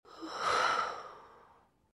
sigh2.wav